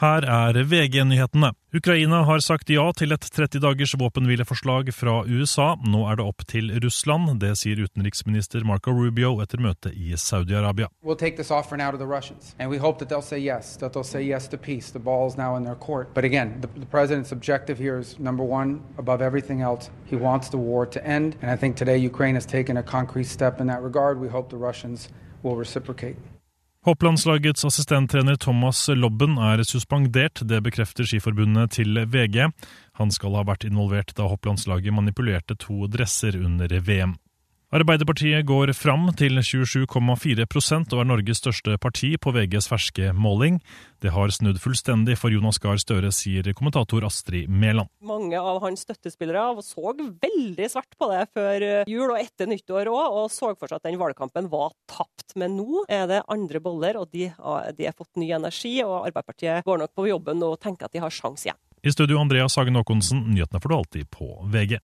Hold deg oppdatert med ferske nyhetsoppdateringer på lyd fra VG. Nyhetene leveres av Bauer Media/Radio Norge for VG.